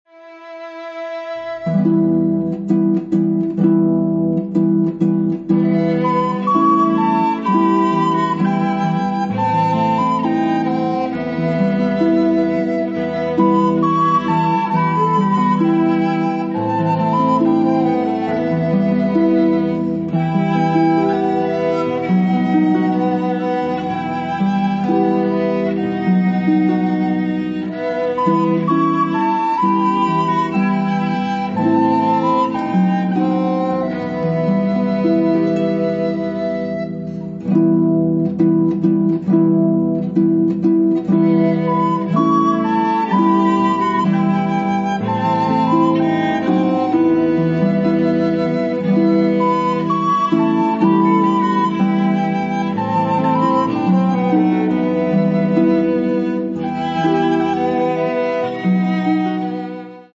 harpist
Genre: Hymns, Praise and Gospel